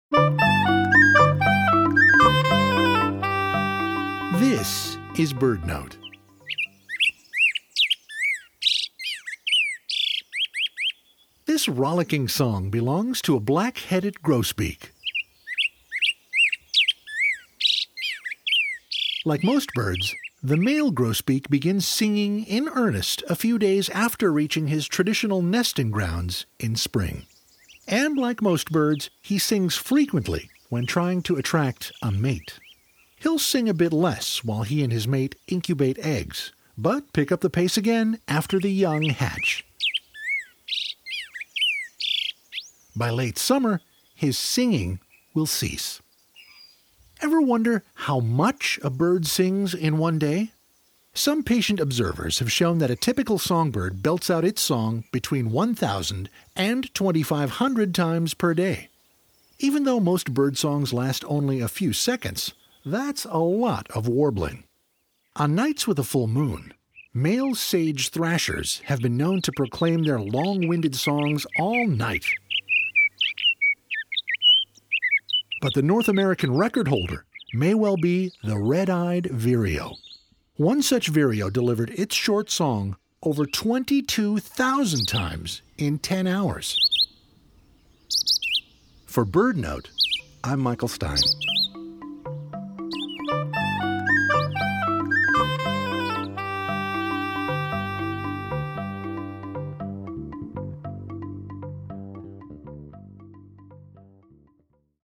But the Yellowhammer doesn’t even come close to the North American record-holder, this Red-eyed Vireo. One such vireo delivered its song over 22,000 times in 10 hours!